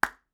• pop sound.wav
Popping of a small yellow cap. Recorded with Sterling ST66